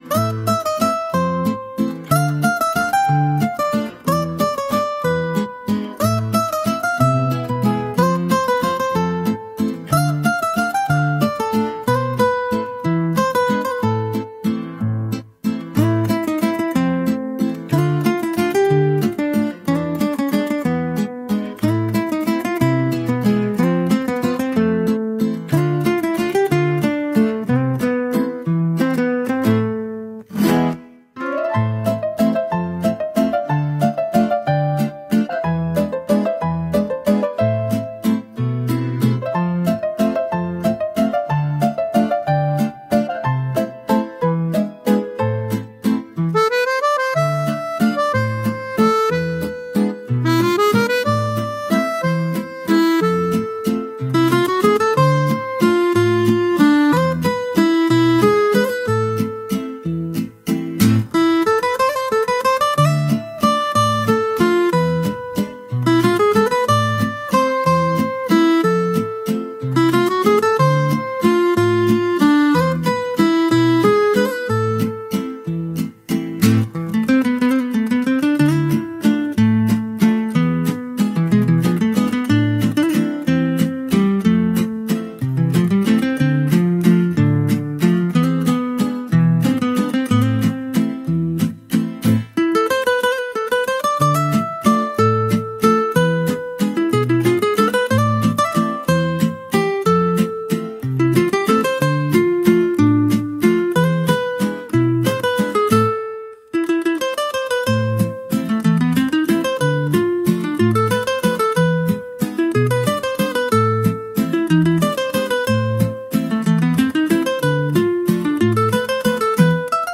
• Жанр: Детские песни